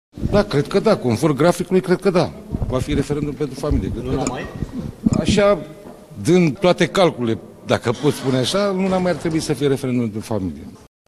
Vicepremierul Paul Sănescu, înaintea ședințe Biroului Permanent al PSD a declarat: „Conform graficului va fi referendum pentru familie, în luna mai”.